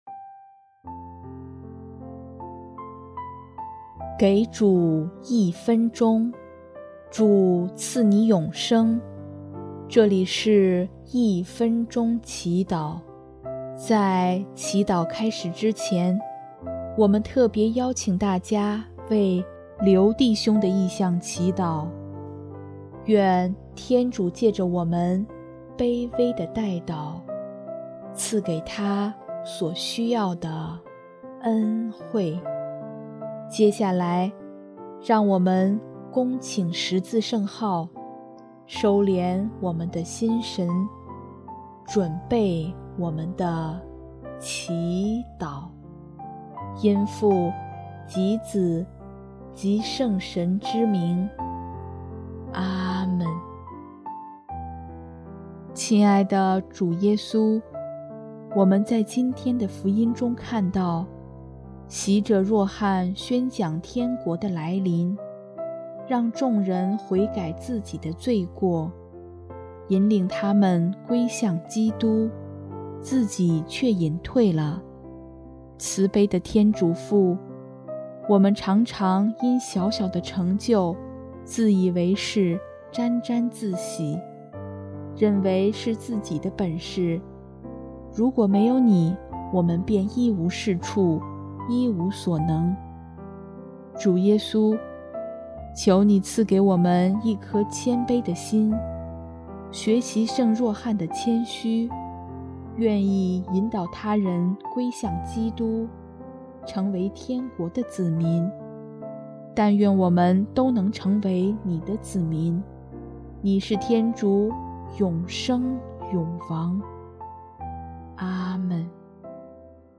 【一分钟祈祷】|12月7日 引领他人归向基督